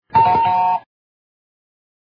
SE (番組中に流れる効果音)
ひひーん 初期からいろんなコーナーの落ちサウンドとして使われ、SEとしても時々登場した。